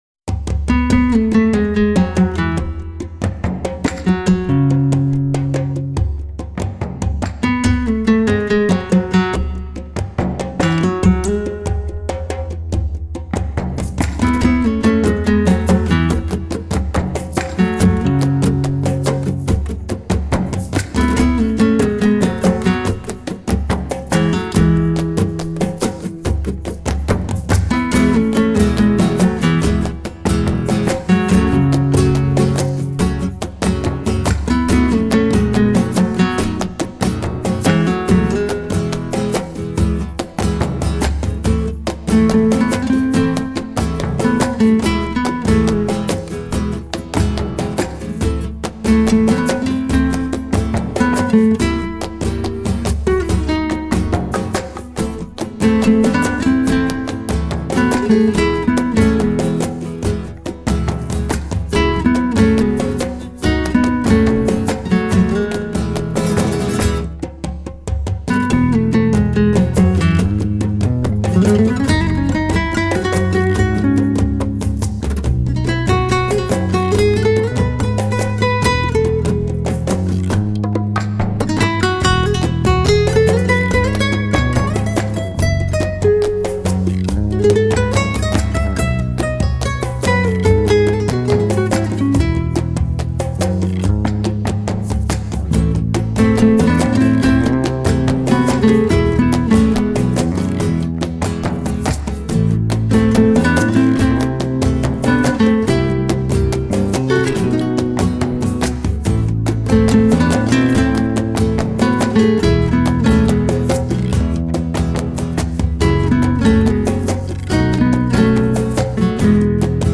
类型:Flamenco